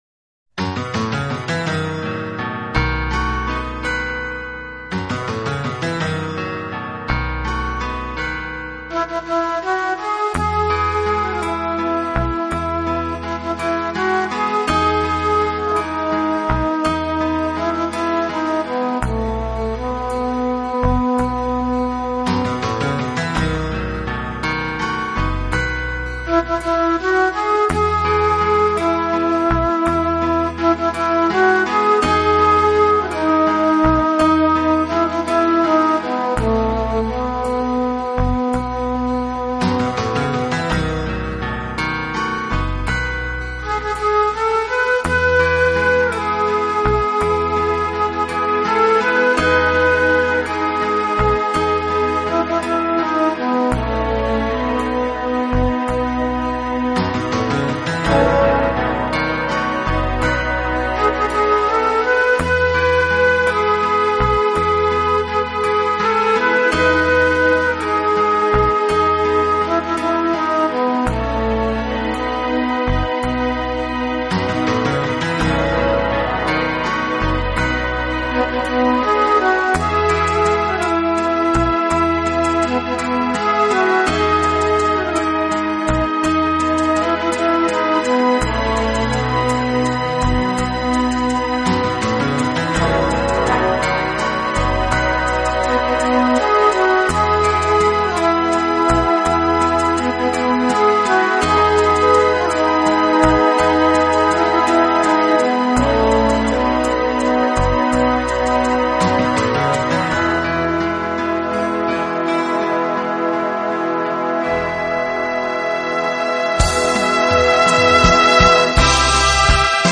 本专辑为双CD，采用世界先进的24Bit Mastering数码录音技术，应该